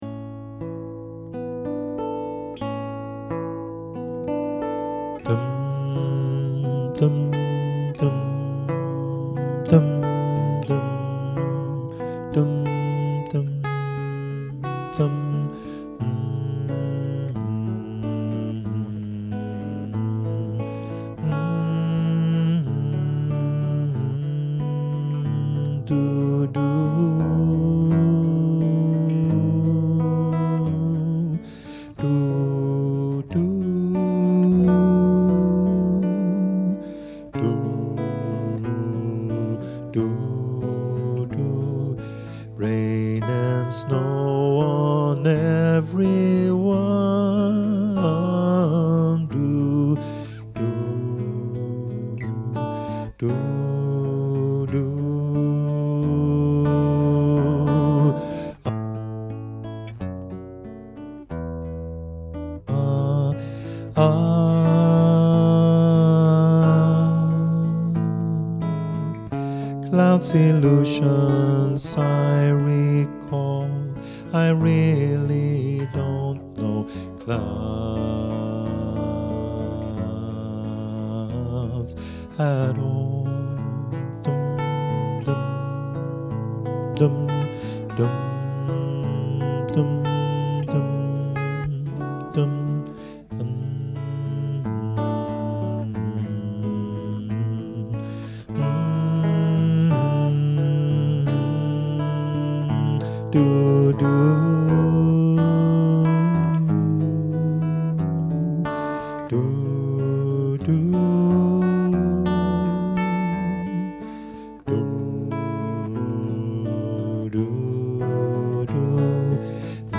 Both Sides Now (damtenor).mp3